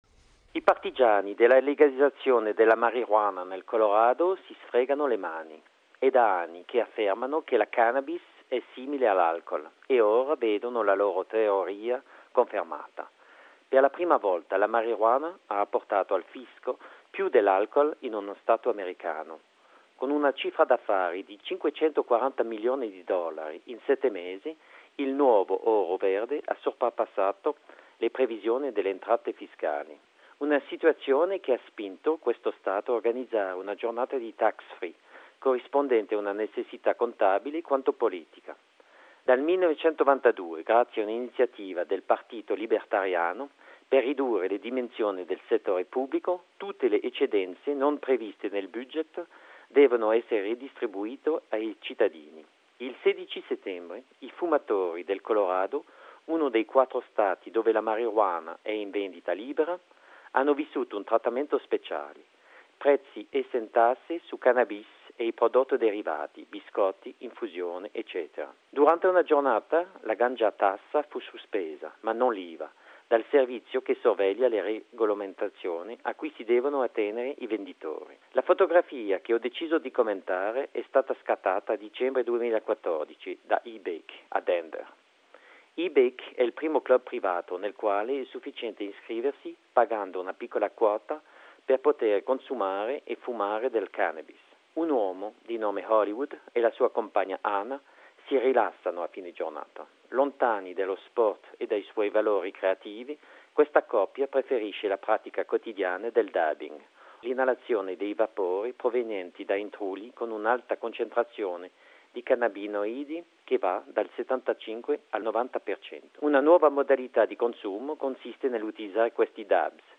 A radio comment about the image “Legal cannabis dabbing in USA”.